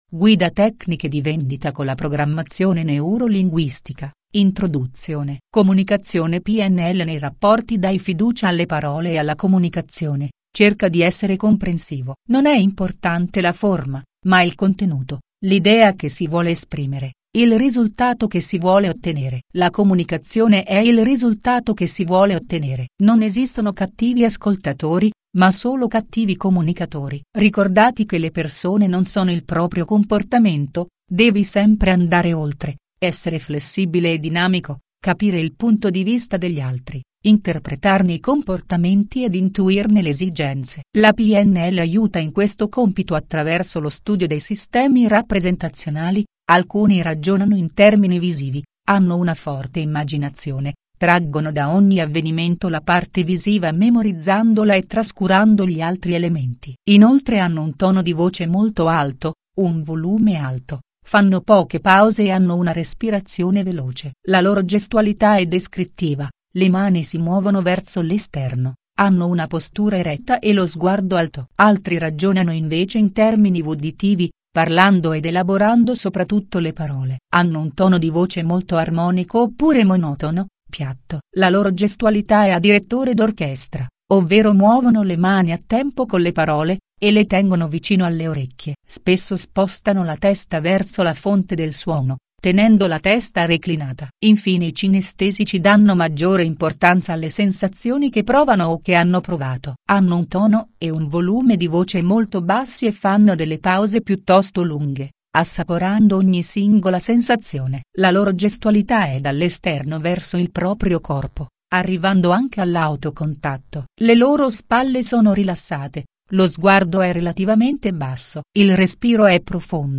Click Play per ascoltare la lettura del testo a voce